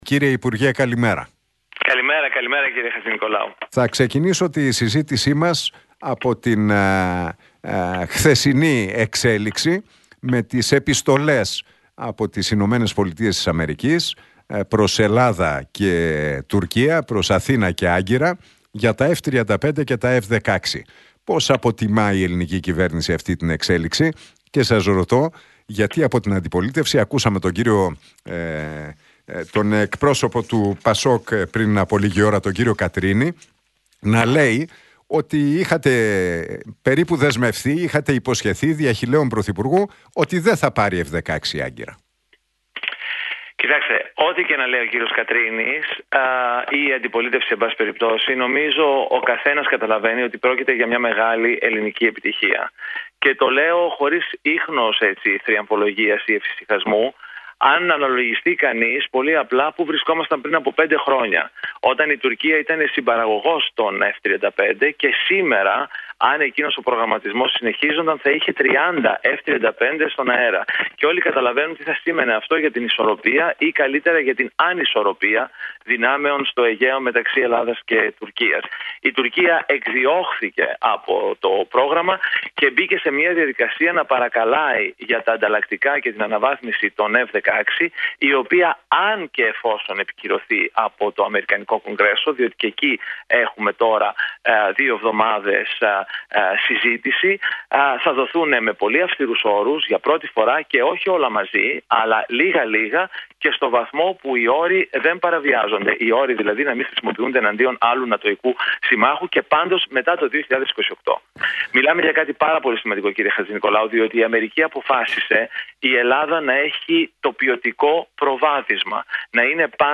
Για τα F-35, την Τουρκία αλλά και το μεταναστευτικό μίλησε ο υπουργός Μετανάστευσης και Ασύλου, Δημήτρης Καιρίδης στον Realfm 97,8 και την εκπομπή του Νίκου Χατζηνικολάου.